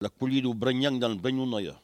Langue Maraîchin
Patois